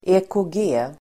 Uttal: [e:kå:g'e:]